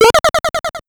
Spring.wav